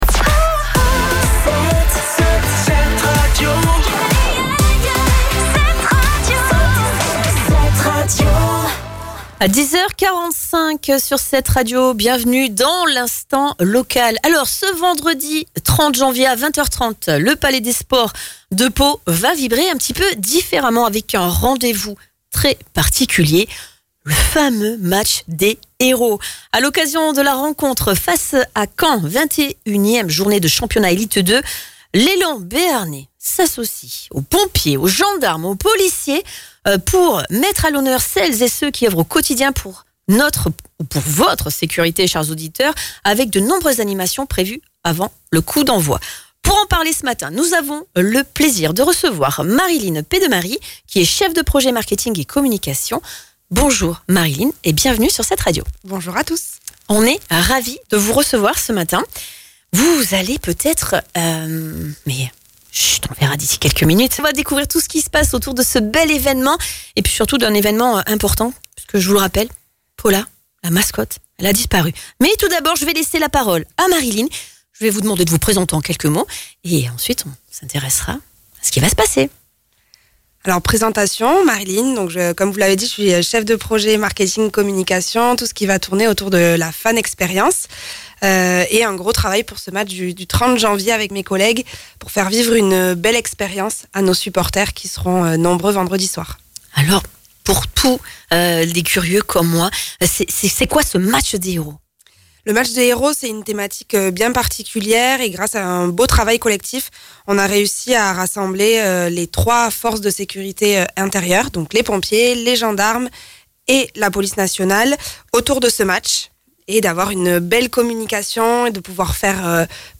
Pour nous en parler, nous avons eu le plaisir de recevoir